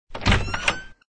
SDoorOpen.ogg